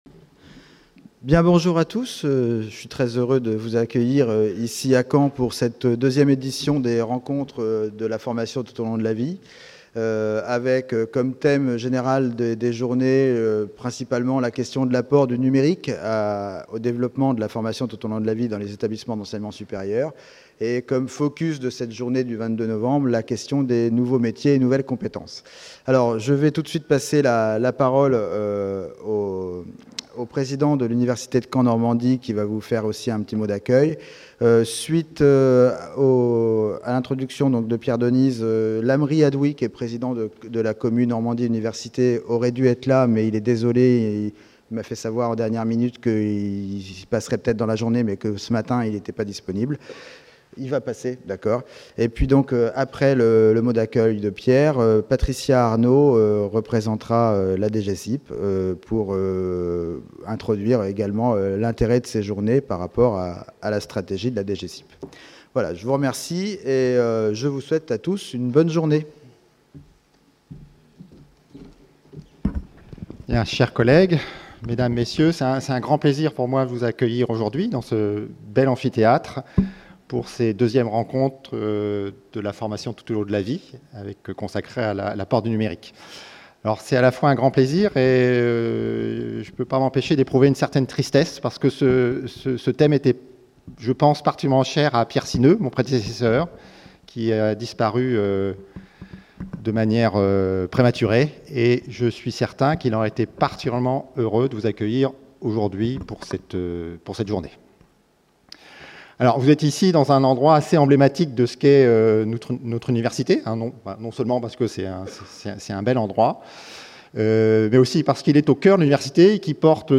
01 Ouverture des 2èmes rencontres concernant le numérique et la formation tout au long de la vie | Canal U